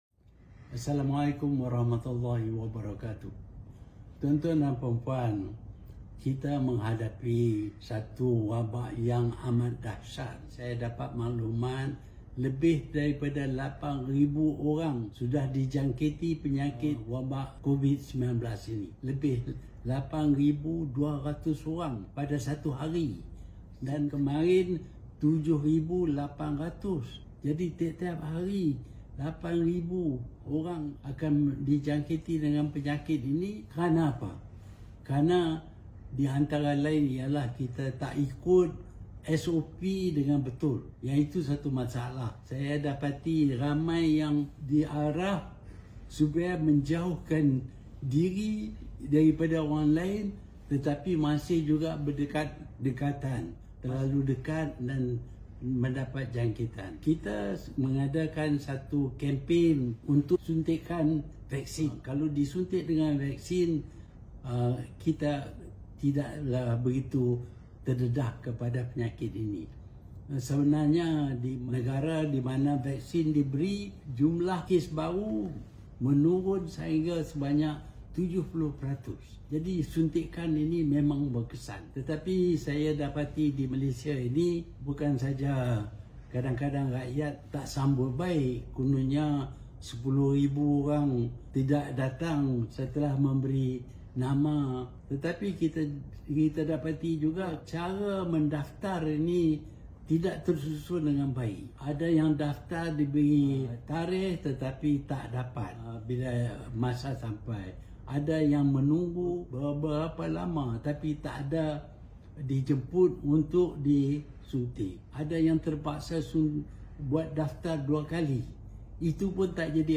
Ikuti kenyataan bekas Perdana Menteri Tun Dr Mahathir Mohamad berkenaan situasi semasa COVID-19 dan keperluan mempermudah cara daftar vaksinasi dan mempercepat pemberian vaksin.